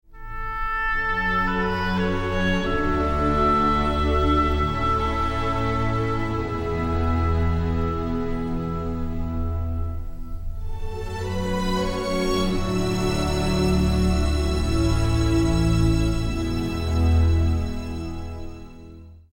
3. Reprendre la mélodie par un autre instrument :